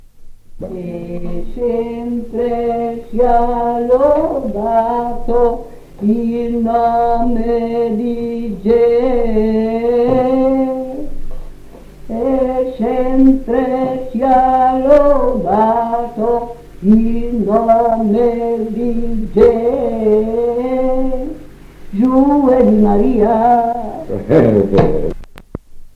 Post 1975. 1 bobina di nastro magnetico.